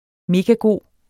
megagod adjektiv Bøjning -t, -e Udtale [ ˈmegaˈgoˀ ] Betydninger meget god; hamrende god SPROGBRUG uformelt Synonymer knaldgod pissegod hammergod herregod Det er en megagod arbejdsplads.